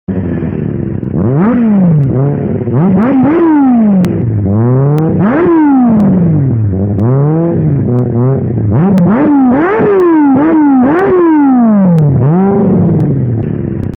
Geluid Kawasaki ====>